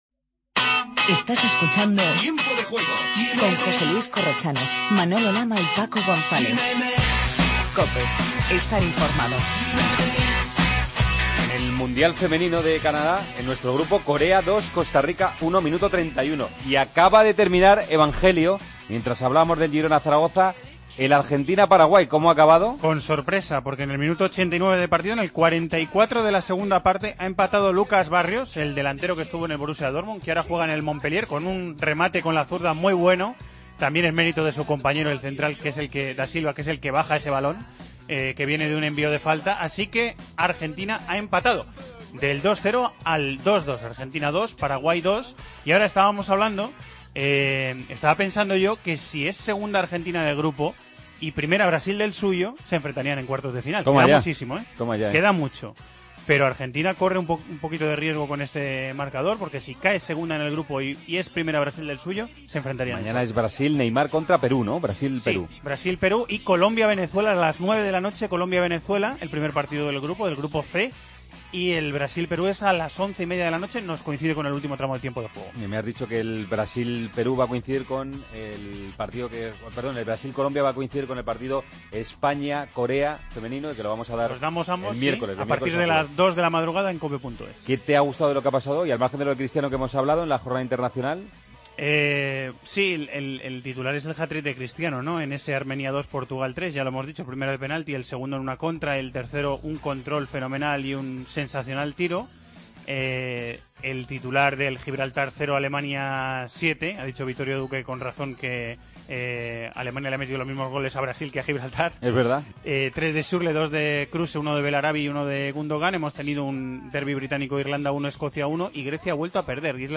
Paso por la Eurocopa femenina de baloncesto.